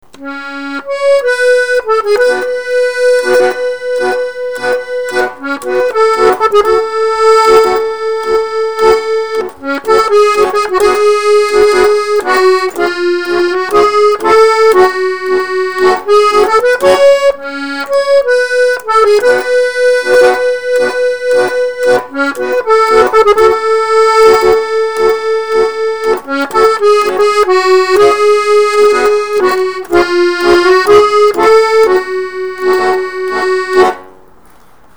Я наиграл, послушайте.